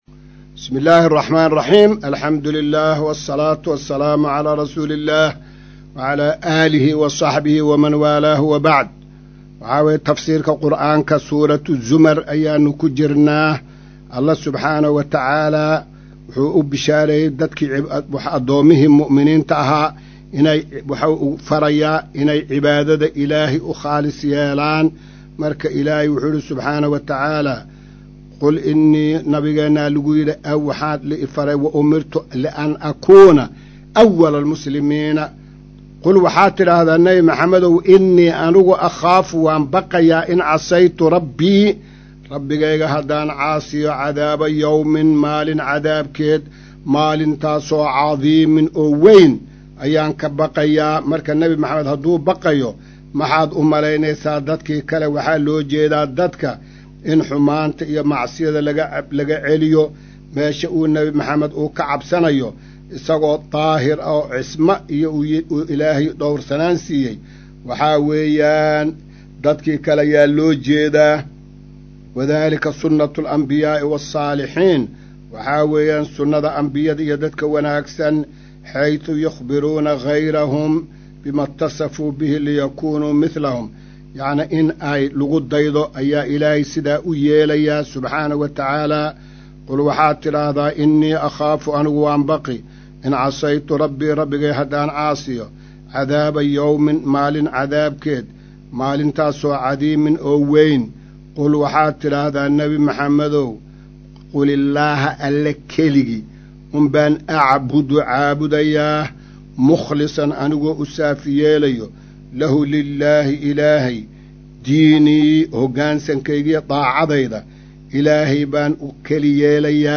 Maqal:- Casharka Tafsiirka Qur’aanka Idaacadda Himilo “Darsiga 219aad”